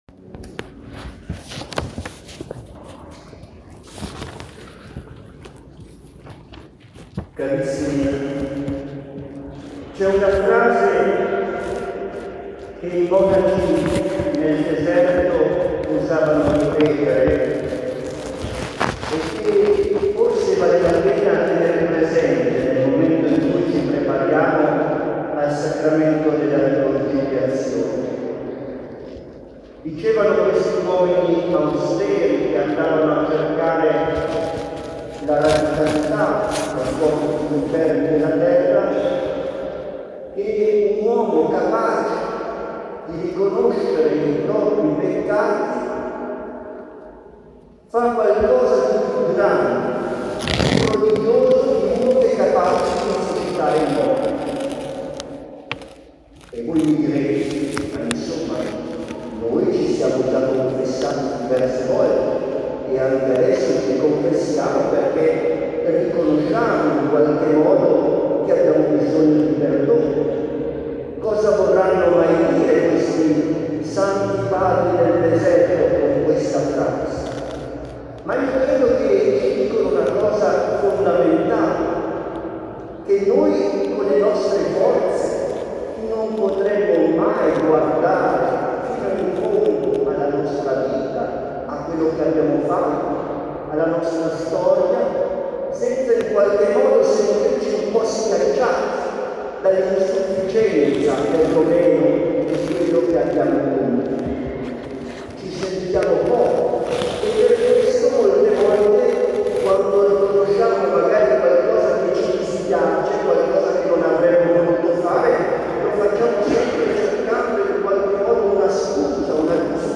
23 agosto – Omelia conclusiva del Pellegrinaggio diocesano a Lourdes (MP3)